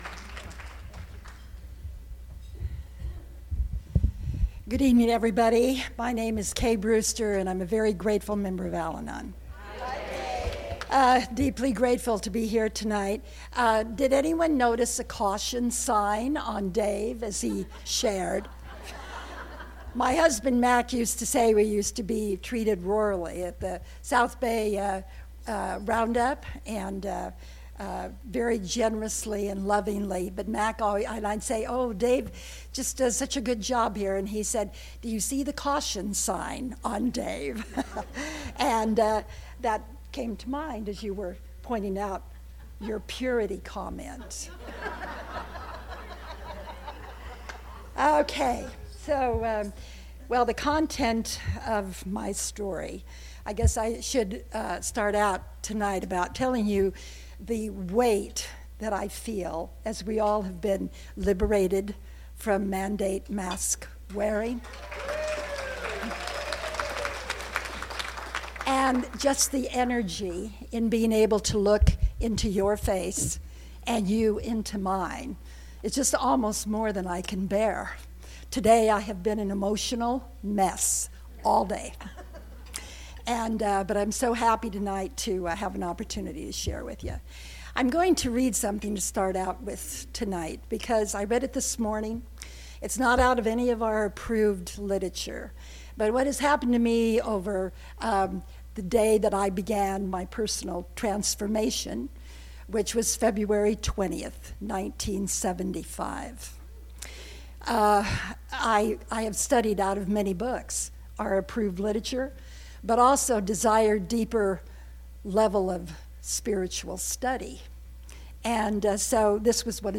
45th Southern California Al-Anon Family Groups Convention